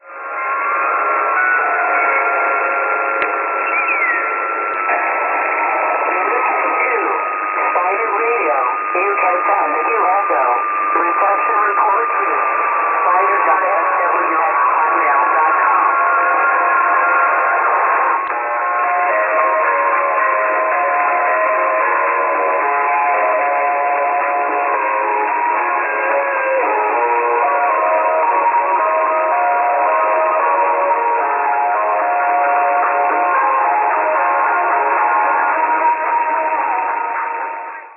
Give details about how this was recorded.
6925 kHz - Spider Radio, Athens, Greece. Now very strong (USB) 20.45- (O=2-4)